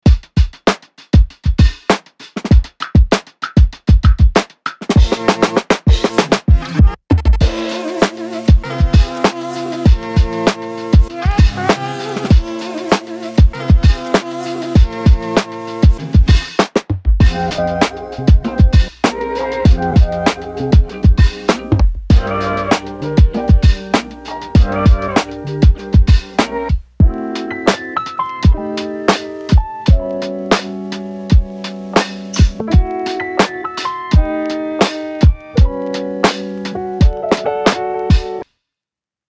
どんなトラックやミックスも、よりハードにヒットするウルトラデッド・ヴィンテージ・ドラムが魂を吹き込む
crunchy_lo-fi[409].mp3